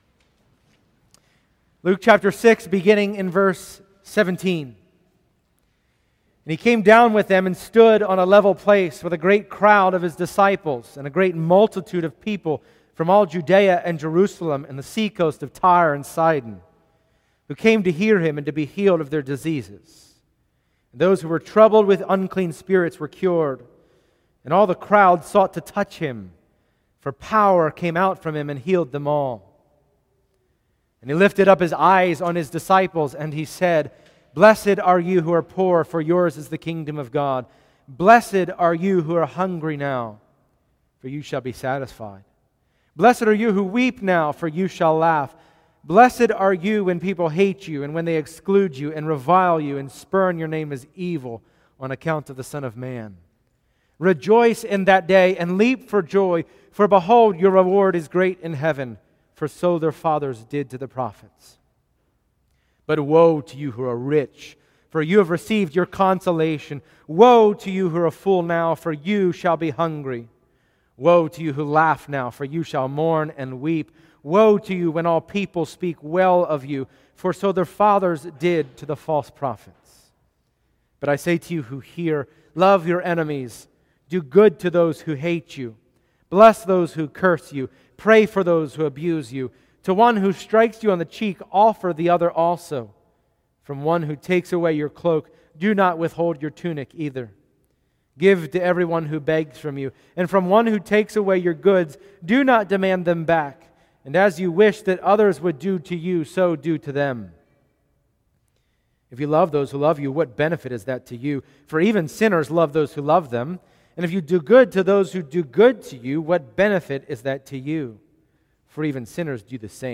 Passage: Luke 6:17-49 Service Type: Sunday Morning %todo_render% Download Files Bulletin « The Kingdom of God